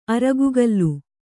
♪ aragugallu